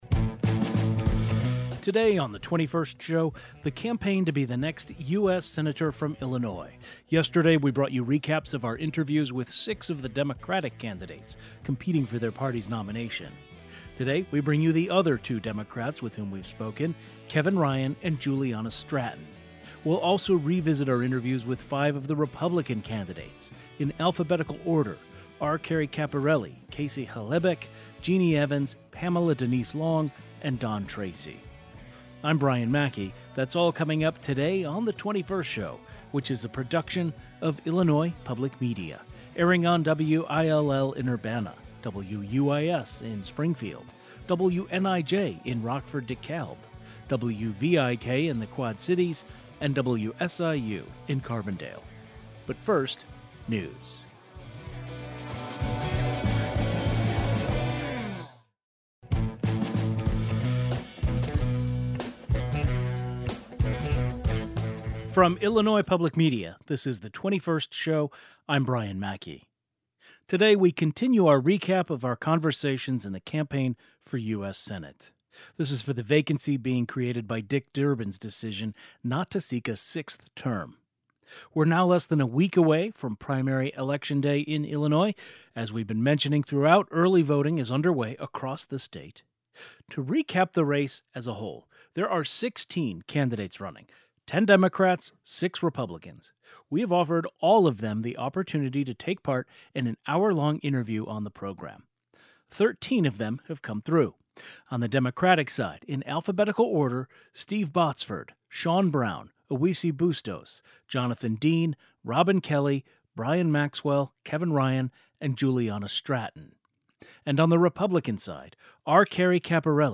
Illinois’ Primary Election Day is less than a week away as voters decide who will represent Democrats and Republicans in the race to succeed retiring U.S. Senator Dick Durbin. In the second of a two-part series, we’ll bring you recaps of our interviews with the candidates.